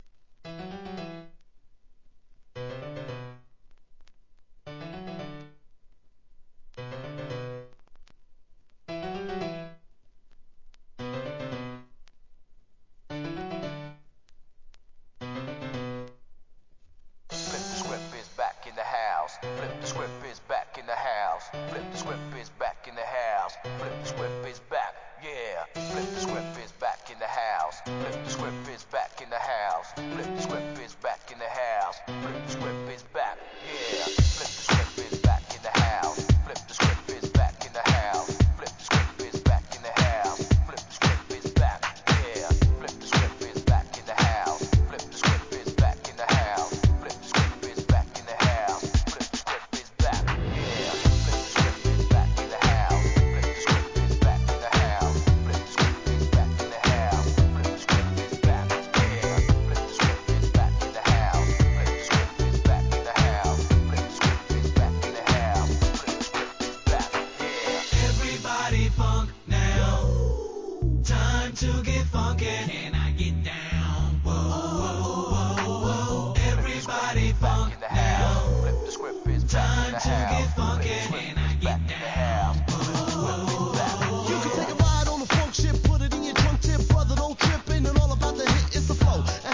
HIP HOP/R&B
イタリア発、歌ものRAP 怒キャッチーノリノリ人気盤!!